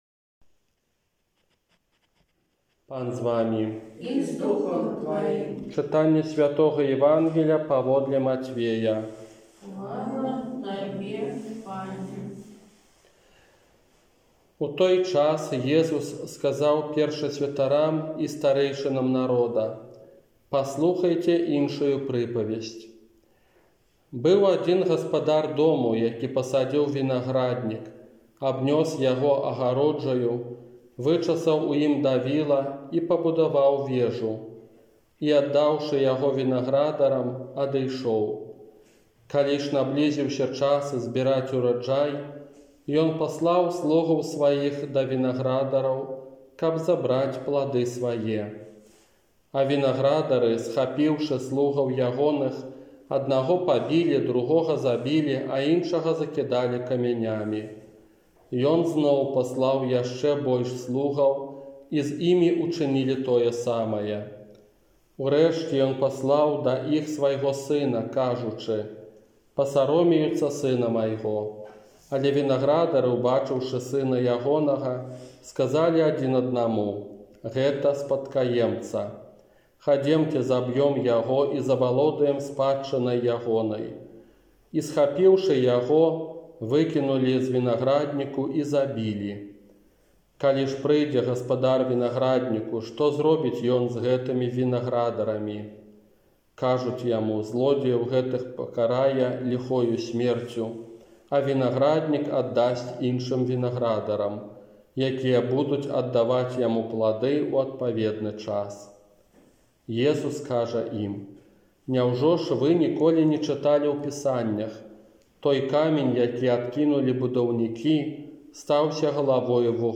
ОРША - ПАРАФІЯ СВЯТОГА ЯЗЭПА
Казанне на дваццаць сёмую звычайную нядзелю 4 кастрычніка 2020 года
Прыпавесць_пра_Вiнаграднiк_казанне.m4a